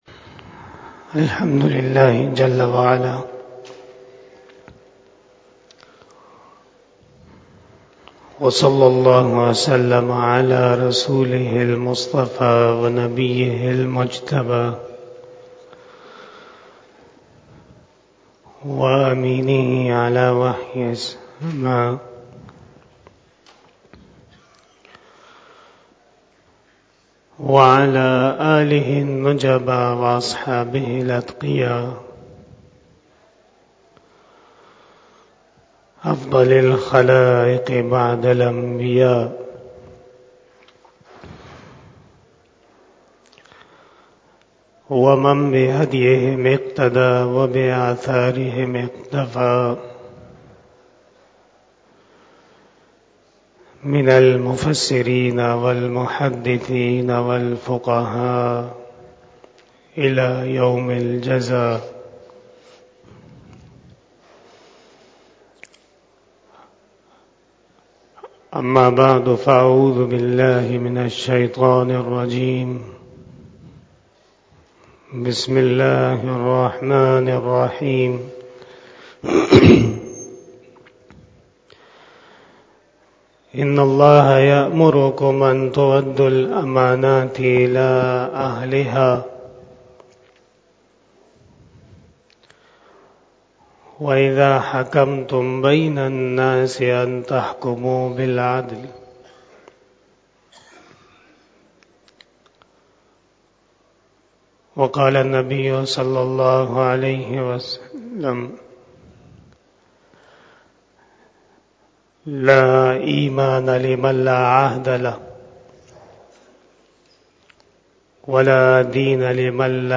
02 BAYAN E JUMMA 13 Janaury 2023 (20 Jamadi Us Sani 1444H)
12:07 PM 345 Khitab-e-Jummah 2023 --